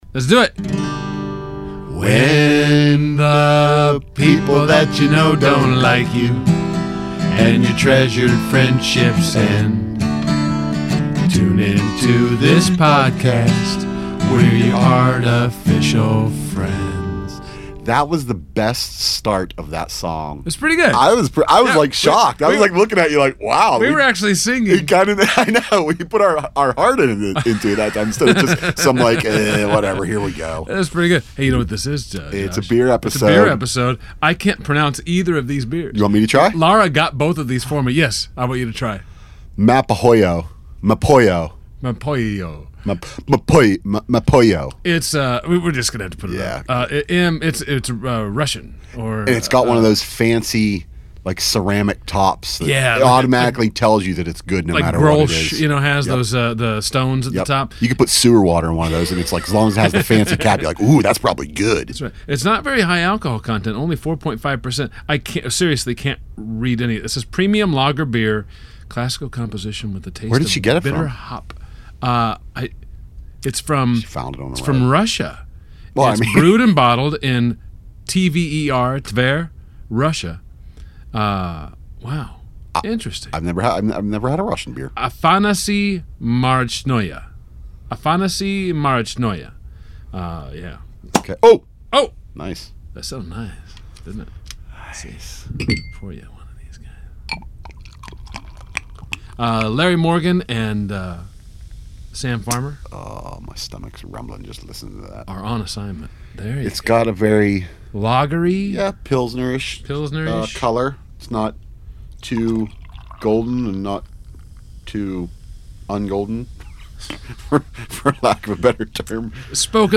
And a special spousal guest calls in to offer some perspicacious viewpoints on the royal wedding.